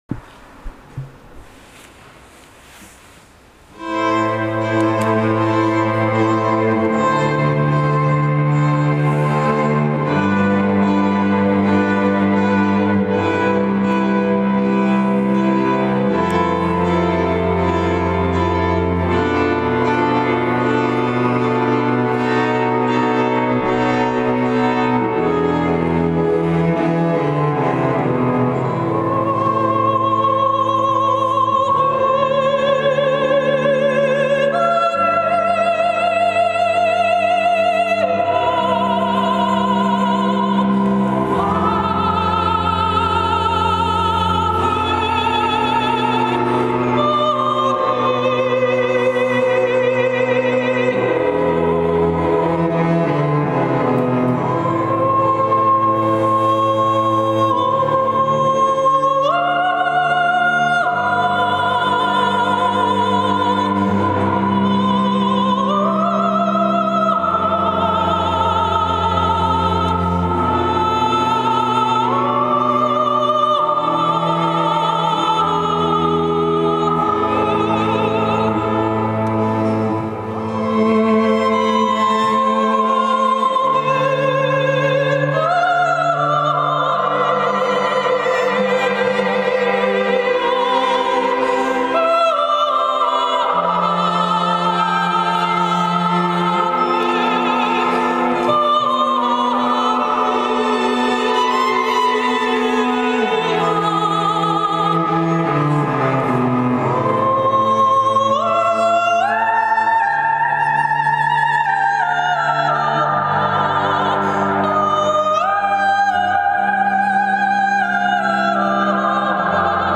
Muzyka na głos i smyczki Najpiękniejsze kompozycje sakralne
Najpiękniejsze kompozycje sakralne
sopran
I skrzypce
altówka
wiolonczela.